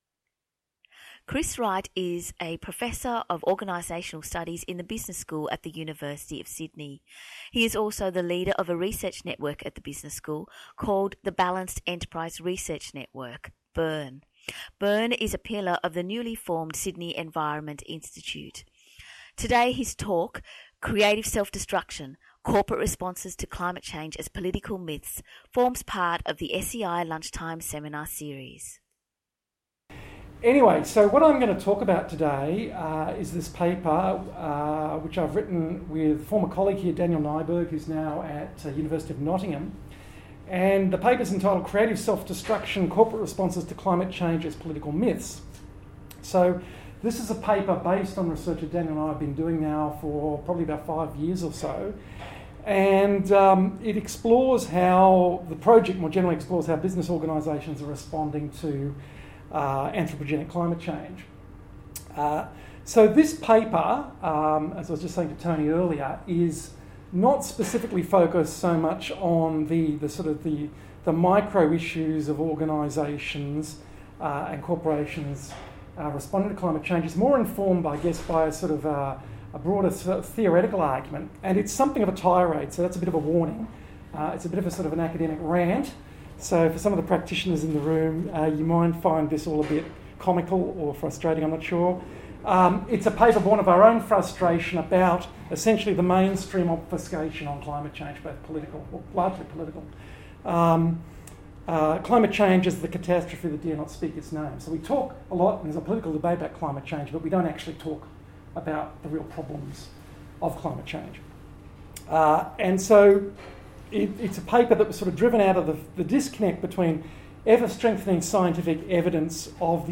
I’ve also presented the paper in a Sydney Environment Institute seminar (audio file below).